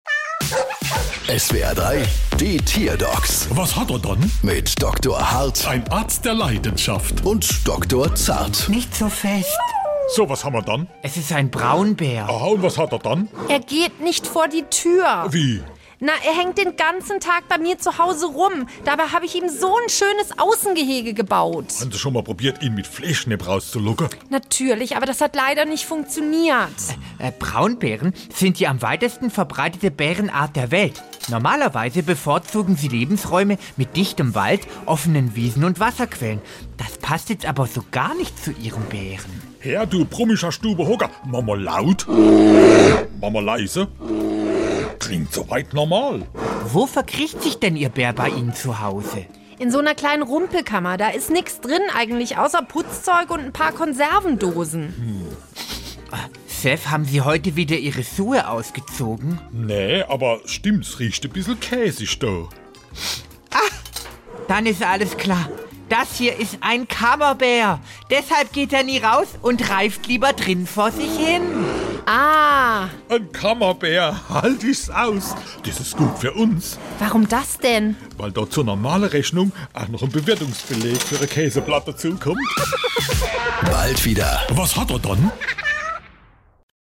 SWR3 Comedy Die Tierdocs: Bär verkriecht sich